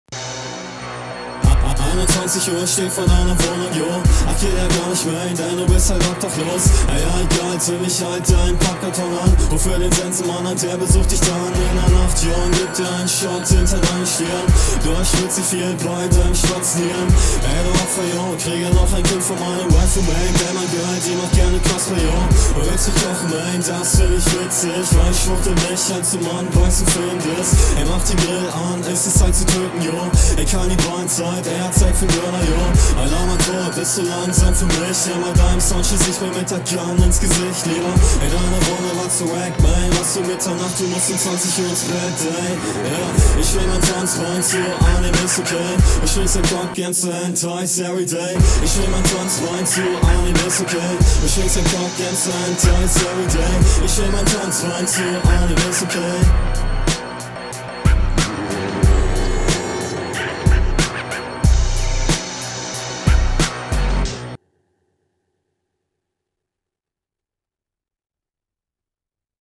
auch interessanter ansatz. mix wieder nicht so ideal. da kommen die lines nicht gut hervor.